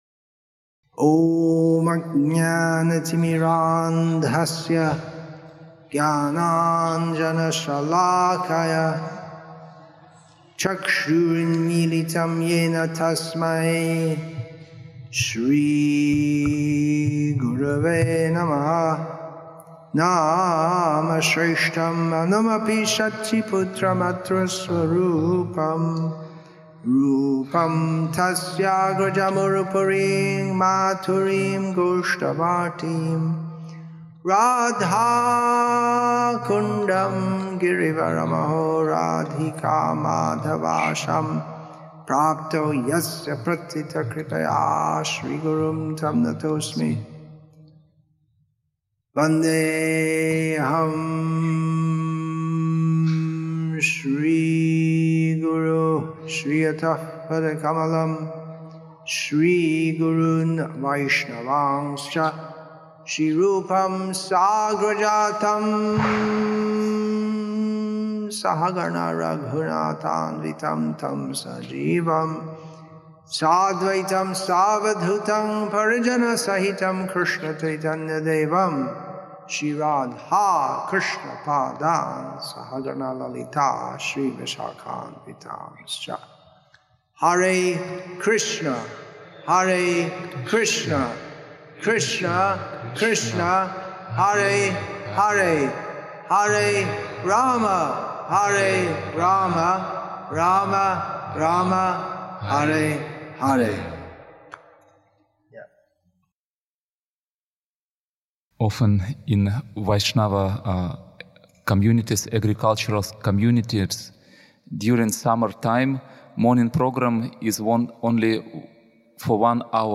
Questions And Answers Session, Part 2